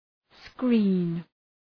Προφορά
{skri:n}